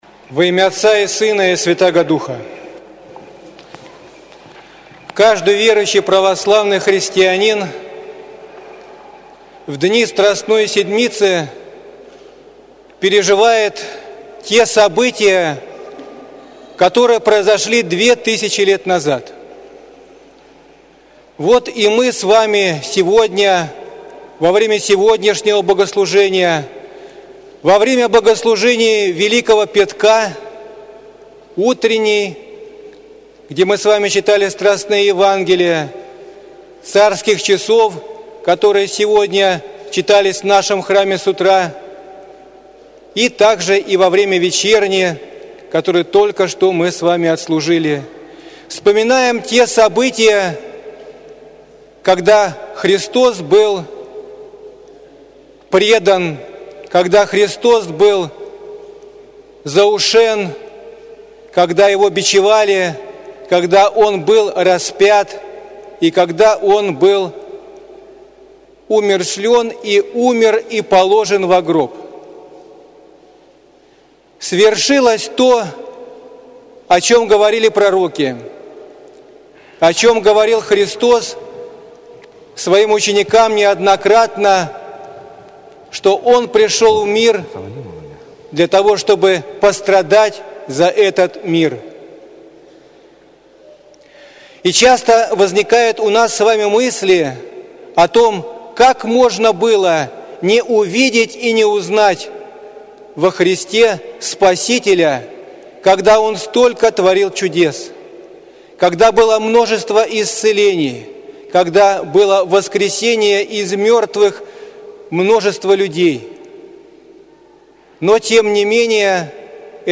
С проповедью к прихожанам обратился иерей